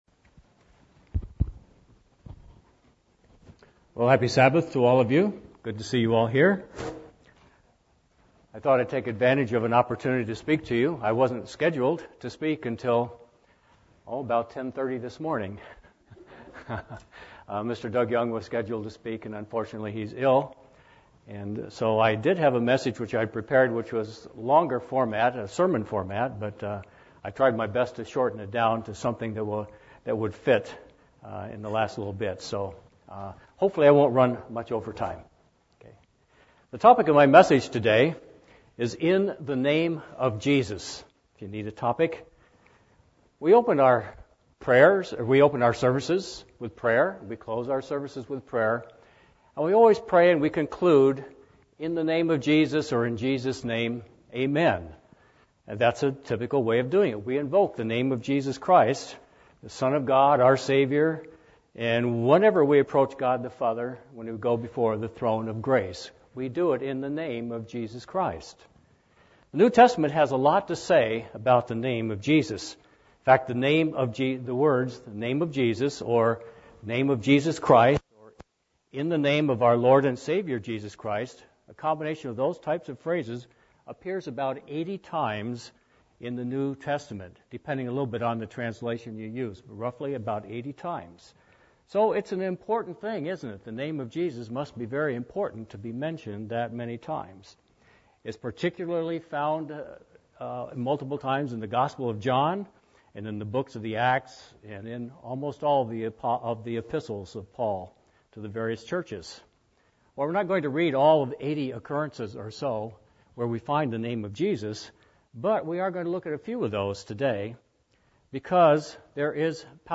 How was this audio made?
Given in San Diego, CA